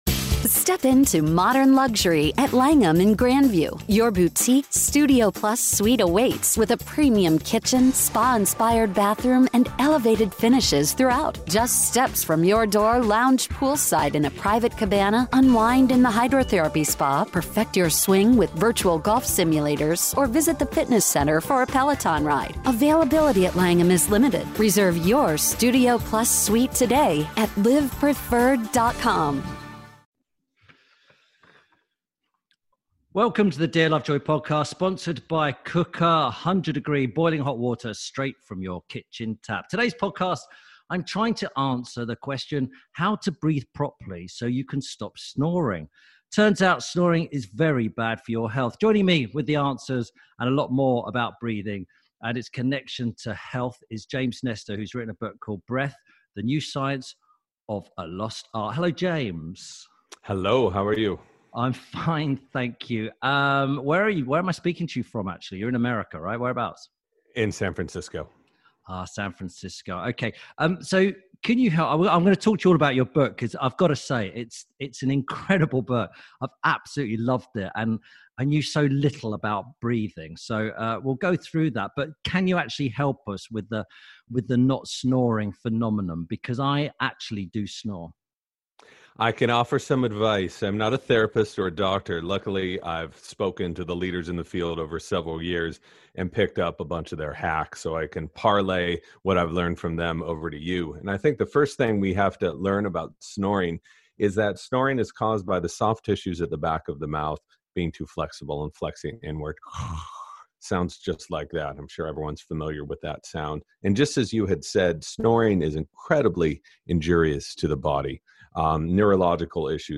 Tim Lovejoy talks to James Nestor about how important breathing properly is for your health.